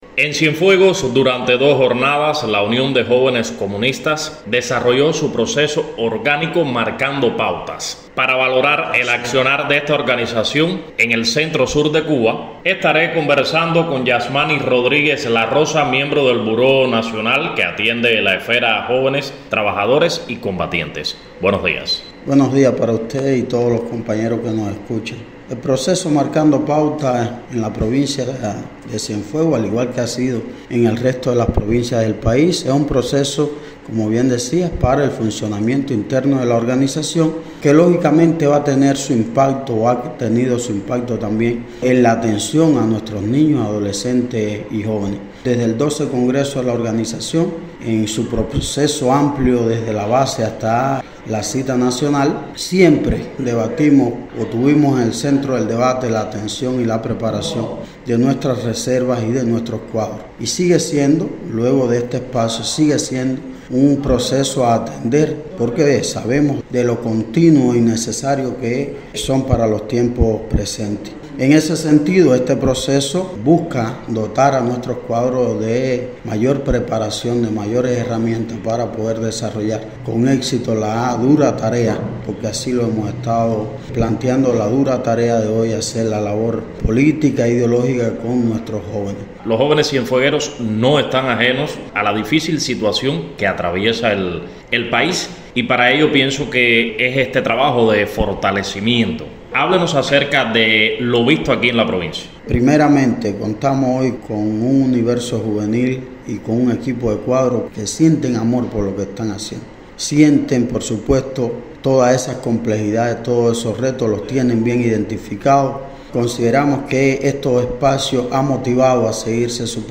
en el Seminario Nacional para Cuadros Políticos, Reservas y Dirigentes Administrativos que se desarrolló en esta provincia.